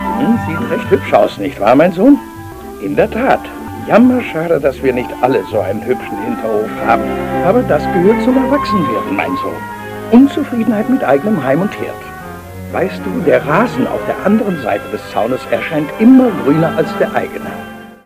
-- alter Käfer